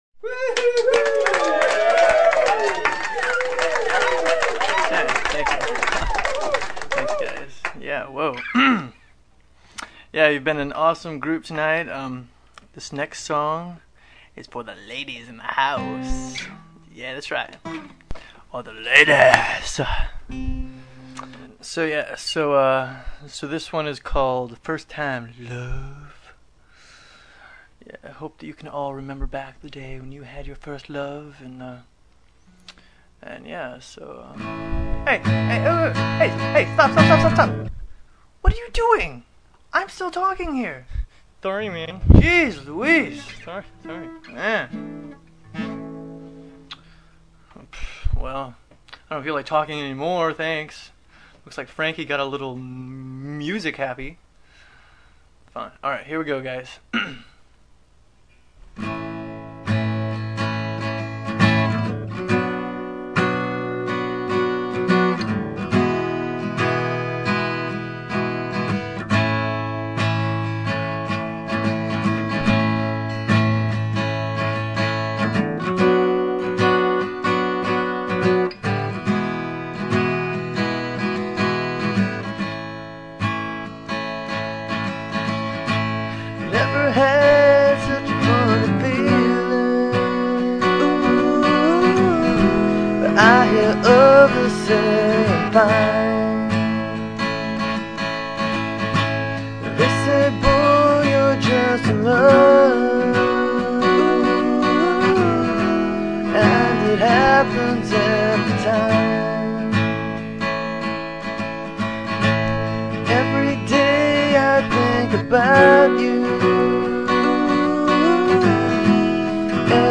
live at the Belbury stage